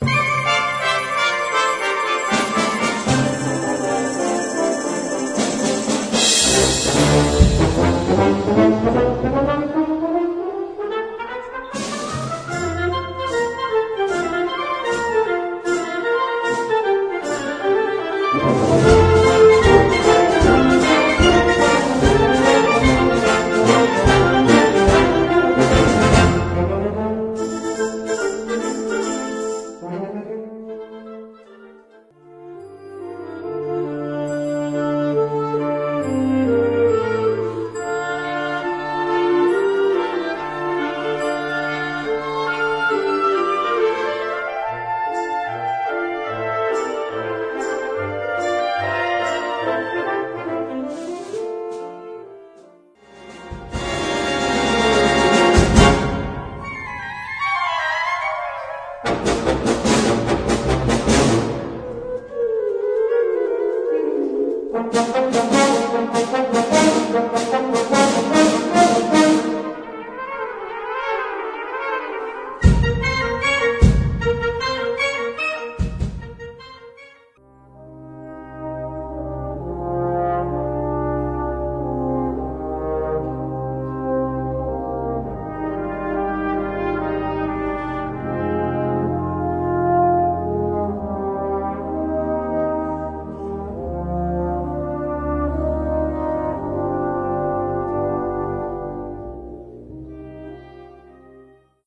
Catégorie Harmonie/Fanfare/Brass-band
Sous-catégorie Musique à vent contemporaine (1945-présent)
Instrumentation Ha (orchestre d'harmonie)
Danses et chants